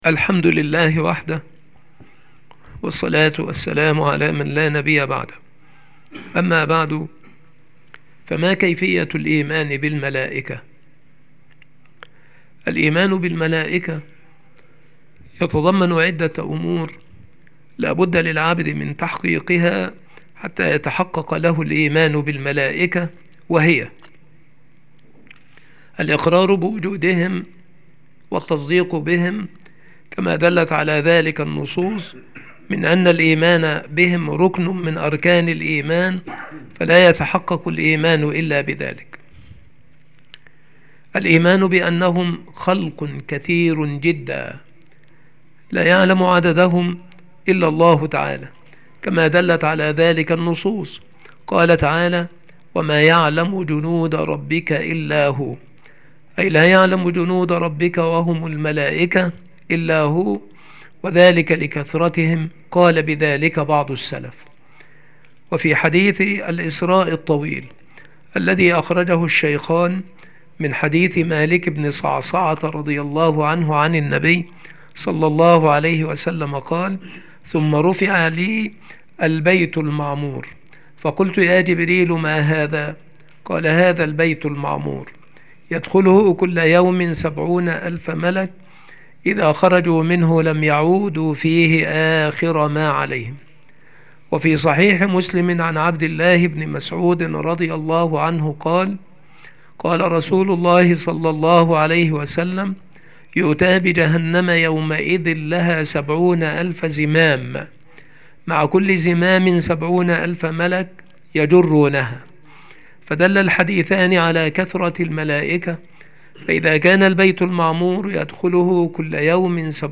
هذه المحاضرة
مكان إلقاء هذه المحاضرة المكتبة - سبك الأحد - أشمون - محافظة المنوفية - مصر